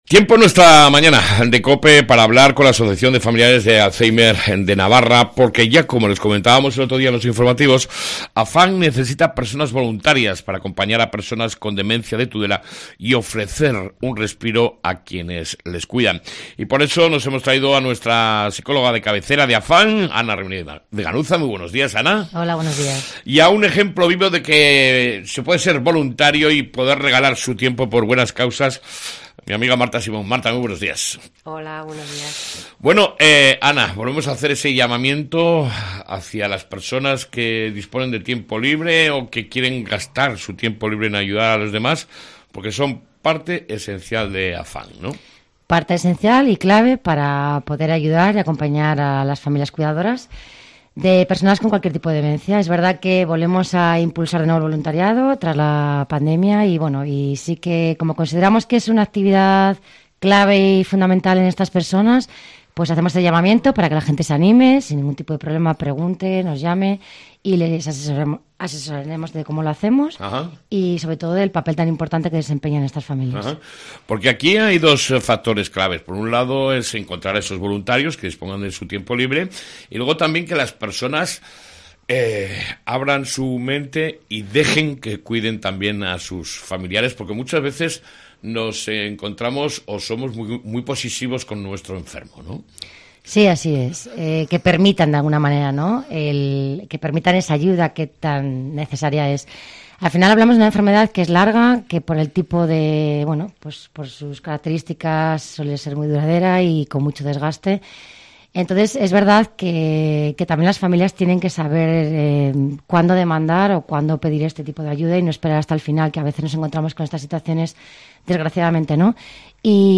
Entrevista con AFAN